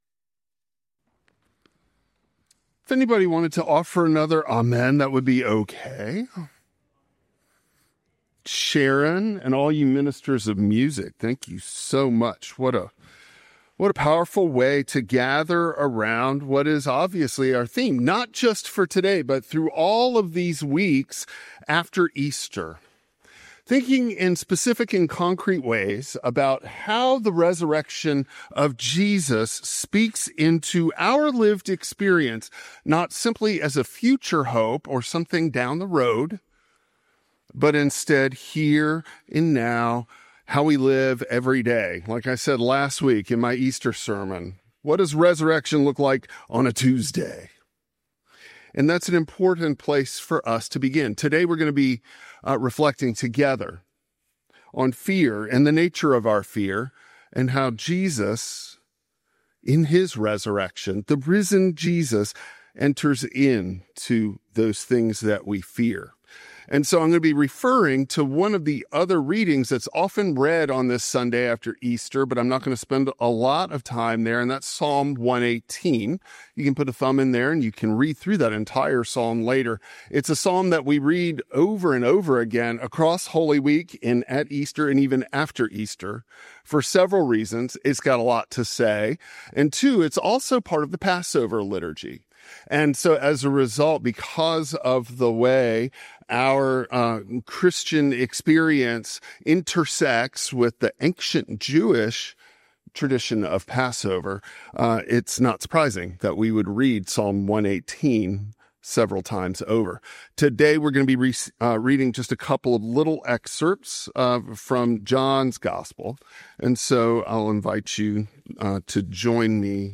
Psalm 118 Service Type: Traditional Service The risen Jesus breathes peace into fear.